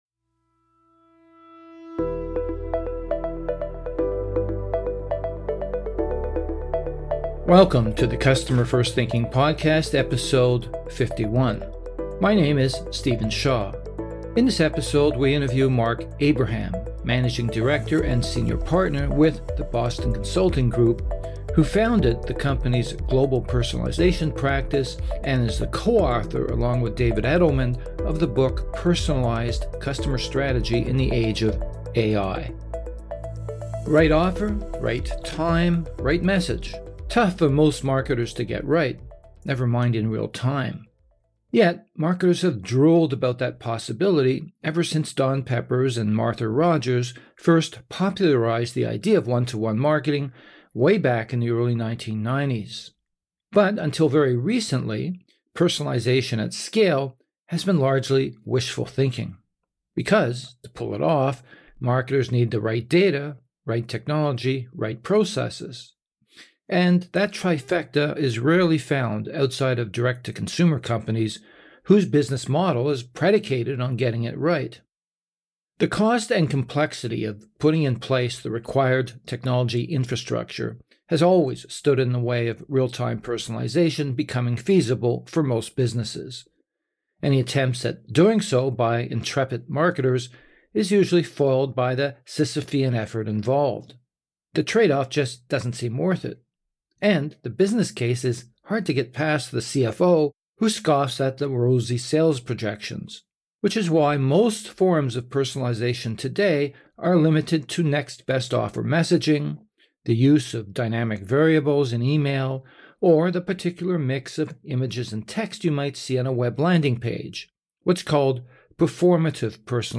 Personalization at Scale: An Interview